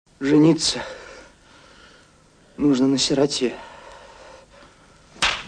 Опять стиральная машина - Дом и семья
Девушка, Вы ничего не перепутали? молодой человек спасибо, пальцы нечего тут мне вниз тыкать ой, я не хотель Модель такая - гремучая - понятно. а снимать корпус занятие не из легких, то есть не выход из ситуации.
Если я скажу равномерно загружайте машину - то вы это и без меня знаете. выжимать на более низких оборотах - вам не пойд╦т, сухо надо. резиновый коврик вы уже подложили... эта пластина начинает со всей дури бить по корпусу - она компенсрует дисбаланс барабана с бель╦м...